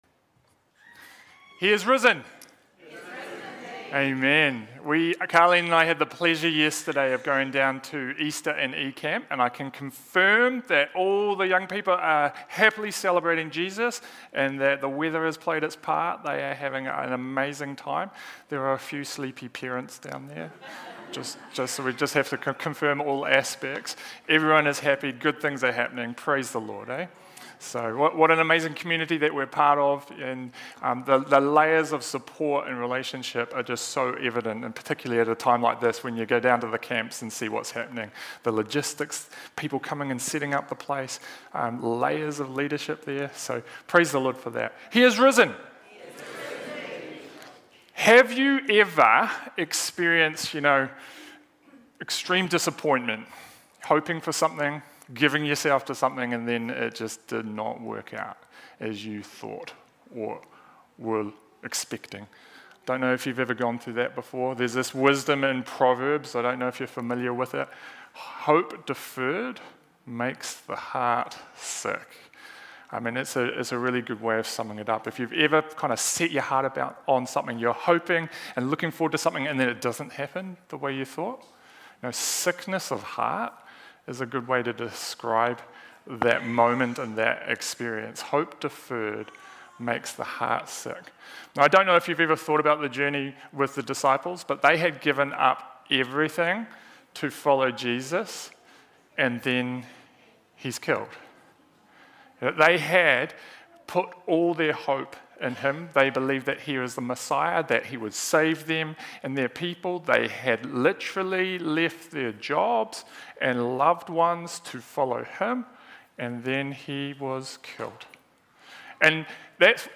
Current Sermon Receiving The Victory Prepared for Victory!